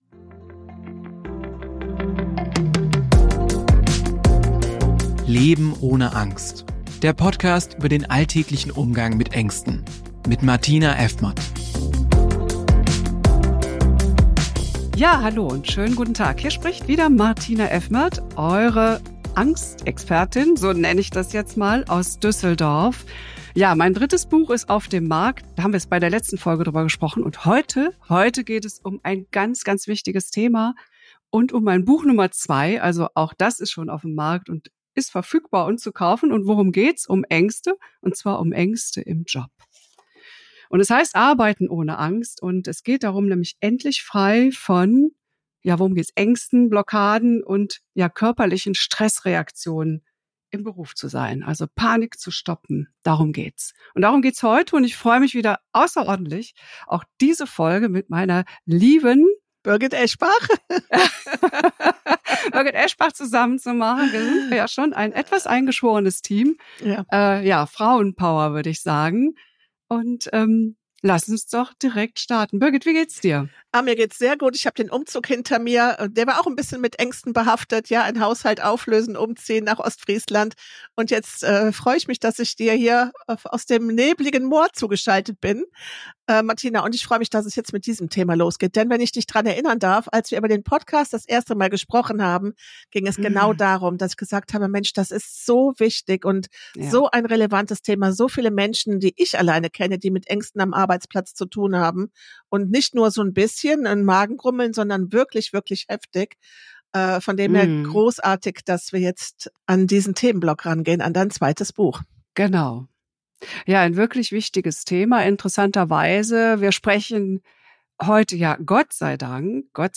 Bonustrack: Progressive Muskelentspannung